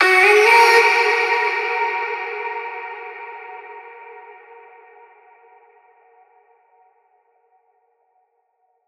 VR_vox_hit_idontknow_D#min.wav